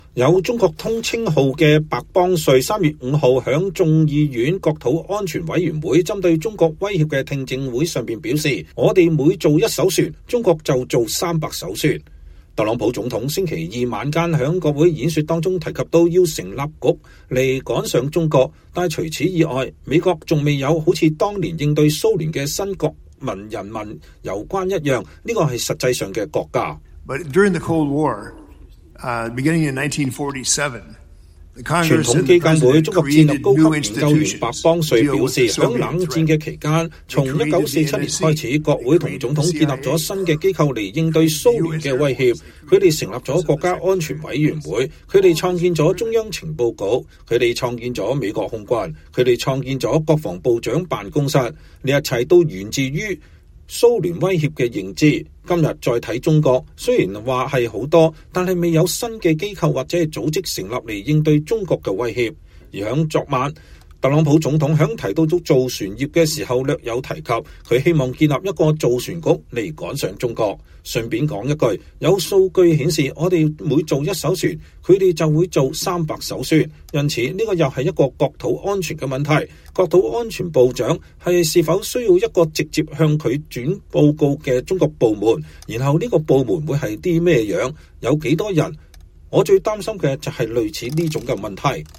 “我們每造一艘船，中國就造300艘船。”有‘中國通’稱號的白邦瑞(Michael Pillsbury)3月5日在眾院國土安全委員會針對中國威脅的聽證會上說，特朗普總統週二晚間在國會演說中提到要成立局來趕上中國，但除此之外，美國還沒有像當年應對蘇聯的新國人民攸關一樣，這實際上的國家。
白邦瑞，傳統基金會中國戰略高級研究員